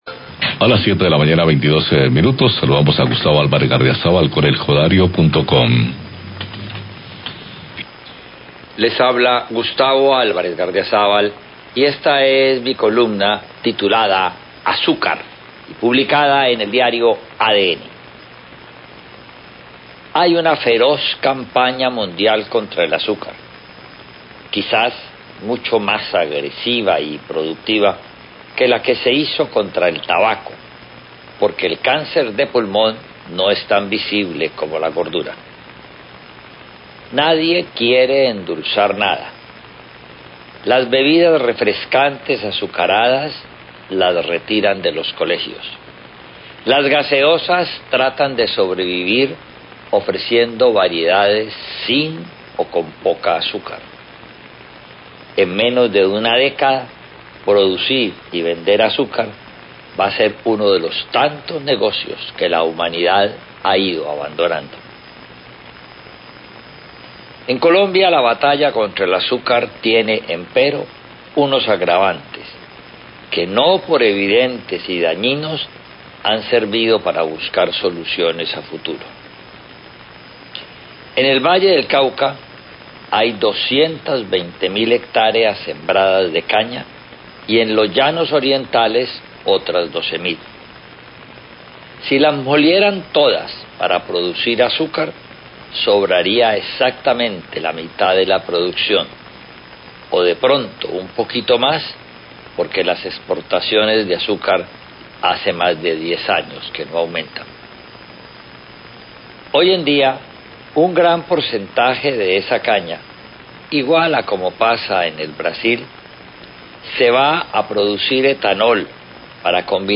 COLUMNA DE OPINIÓN- CAMPAÑA CONTRA EL CONSUMO DE AZÚCAR
Radio